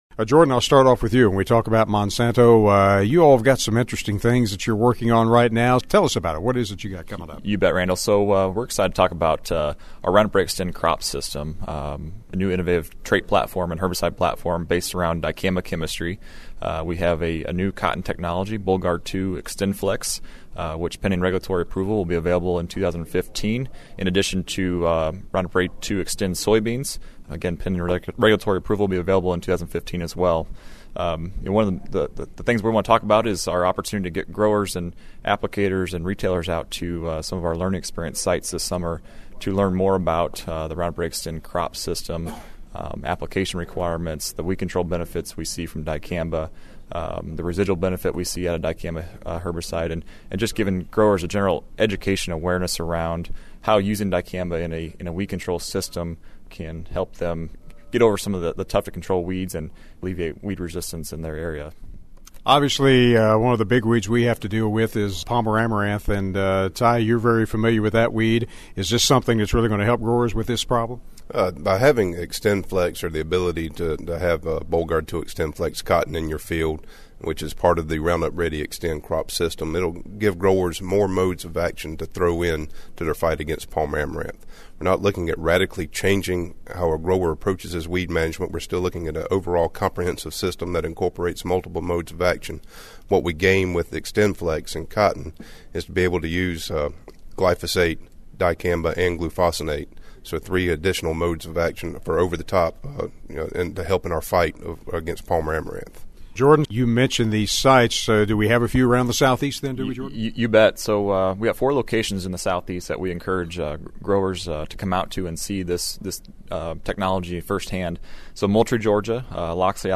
Monsanto is one of the many exhibitors at this year’s Farm & Gin Show in Memphis, Tennessee.